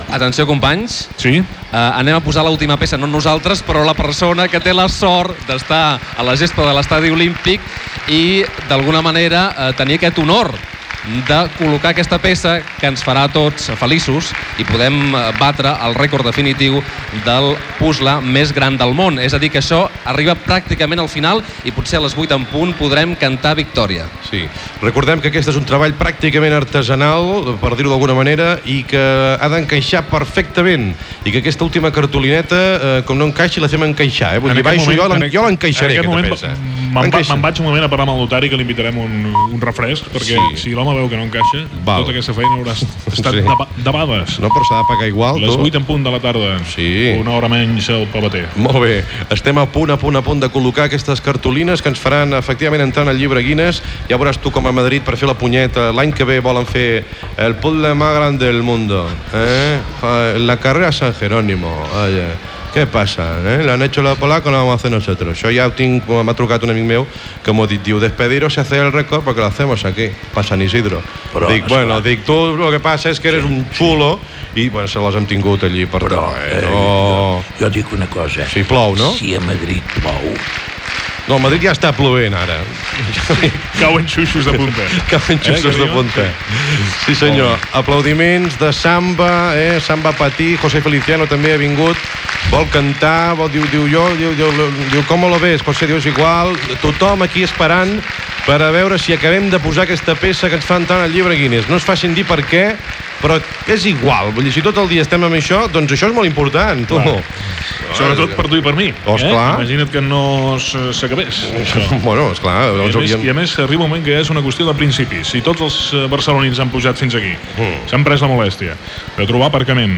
Narració dels últims minuts de la construcció del puzle.
FM